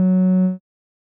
Techmino/media/sample/bass/22.ogg at beff0c9d991e89c7ce3d02b5f99a879a052d4d3e
添加三个简单乐器采样包并加载（之后用于替换部分音效）